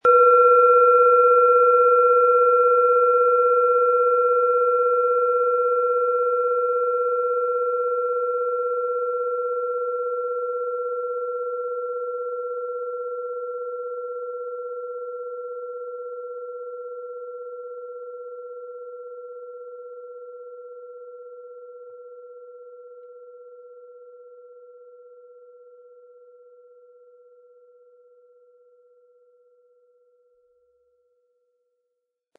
Planetenton 1
Seit Generationen werden in dem Meisterbetrieb in Indien Klangschalen hergestellt, aus dem diese Lilith Planetenton-Klangschale stammt.
Um den Originalton der Schale anzuhören, gehen Sie bitte zu unserer Klangaufnahme unter dem Produktbild.
Den passenden Klöppel erhalten Sie umsonst mitgeliefert, er lässt die Schale voll und wohltuend klingen.